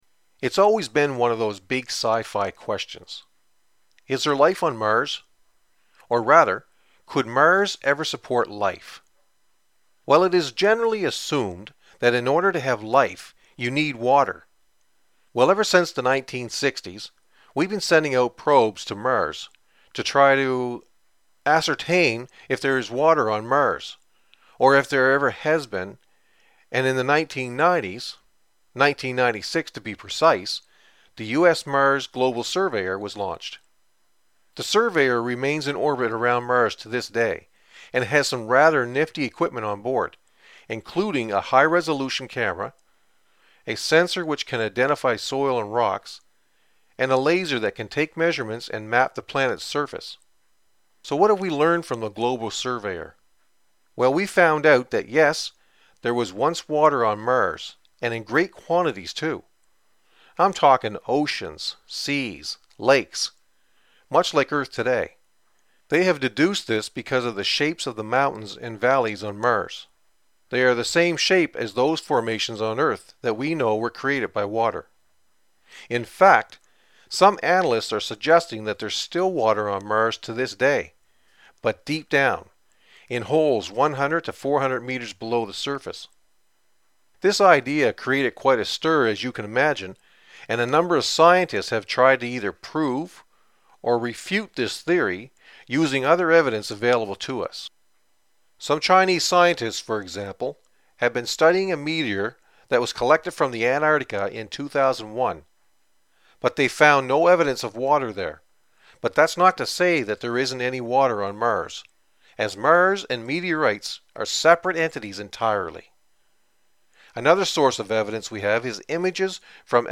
Sample Lecture
toefl_speaking_pt6_lecture.mp3